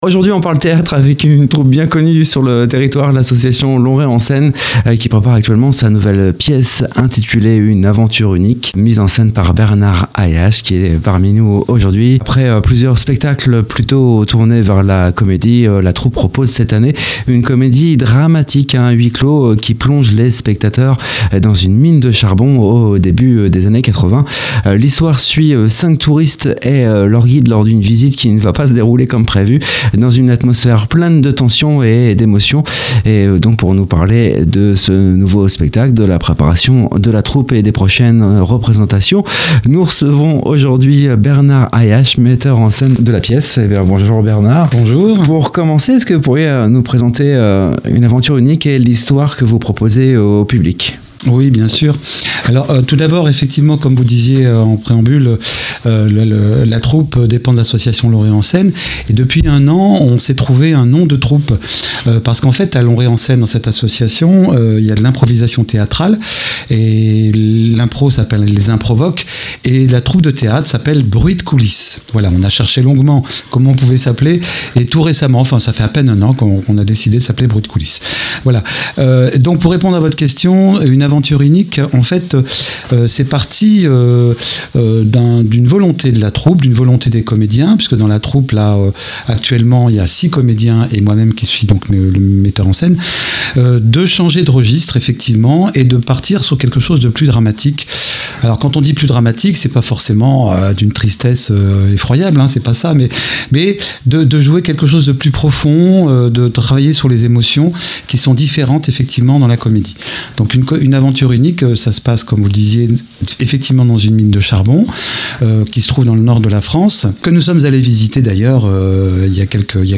Une interview qui plonge dans les coulisses de la création théâtrale amateur et qui met en lumière l’engagement et la passion de la troupe Bruits d’coulisses.